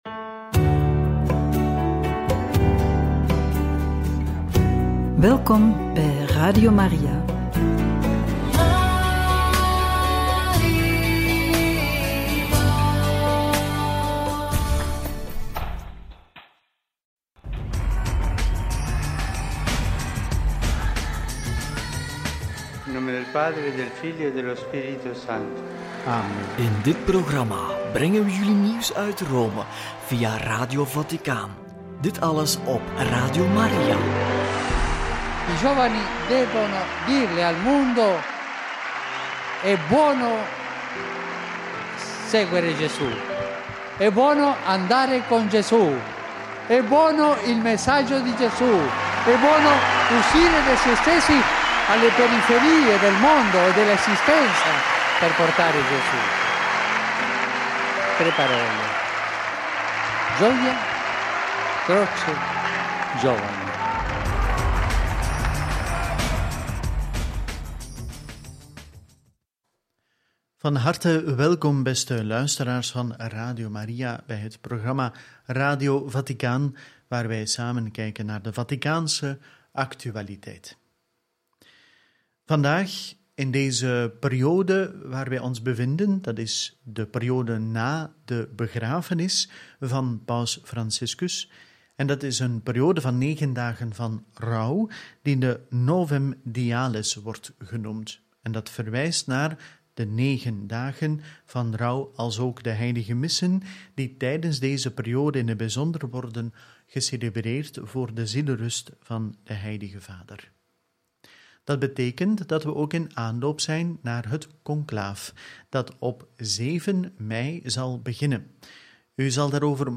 Novemdiales – Luister naar de homiliën van kardinalen Parolin, Baldissera en Gambetti – Radio Maria